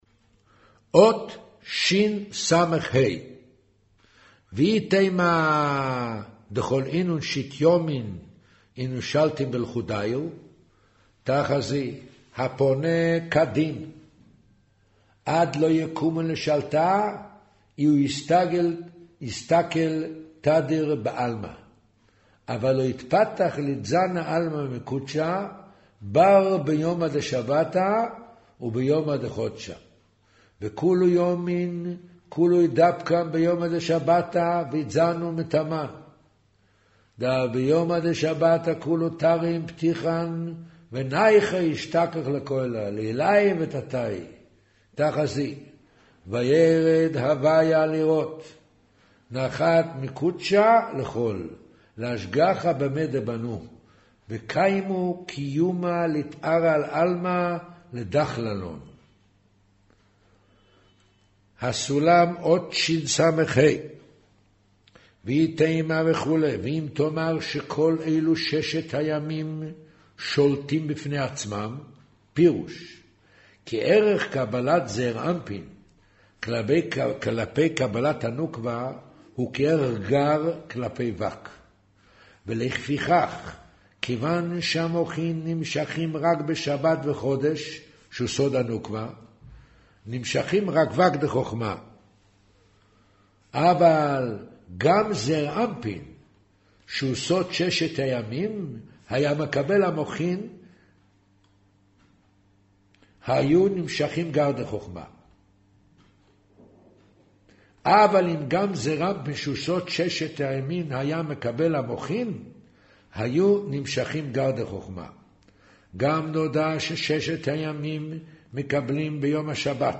אודיו - קריינות זהר, פרשת נח, מאמר שער החצר הפנימית